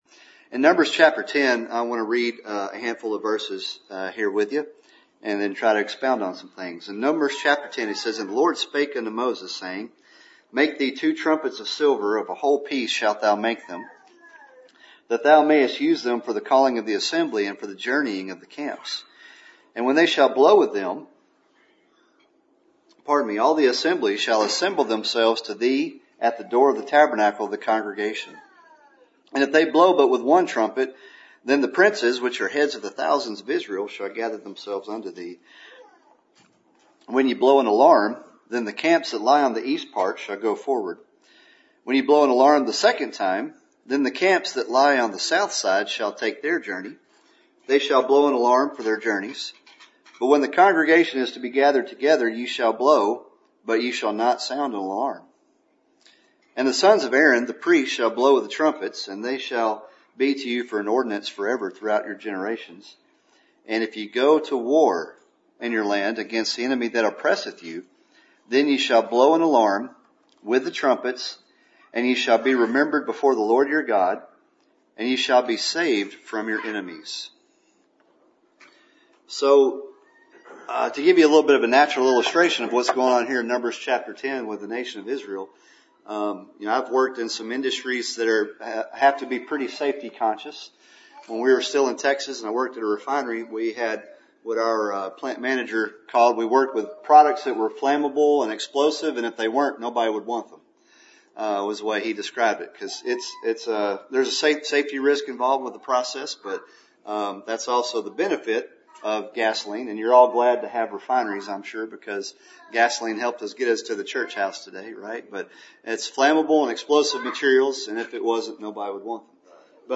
Numbers 10:1-9 Service Type: Cool Springs PBC Sunday Evening « Take now thy son